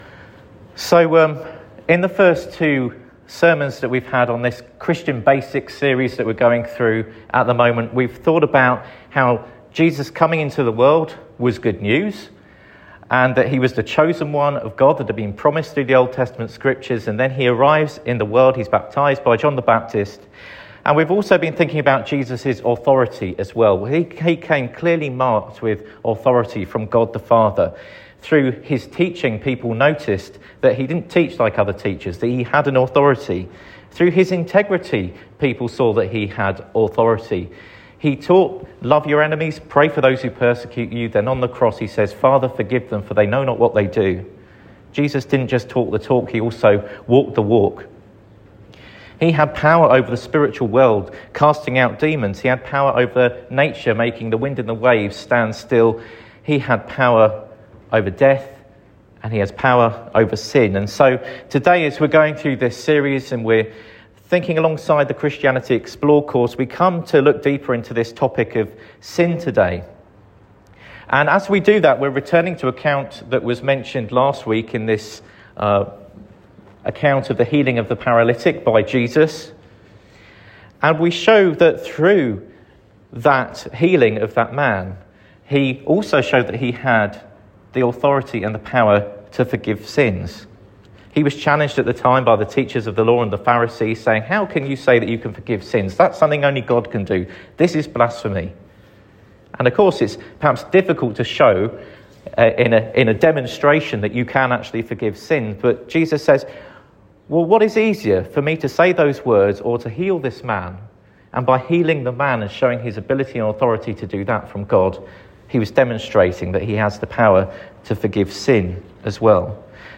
Sermon for Sunday 18th January 2026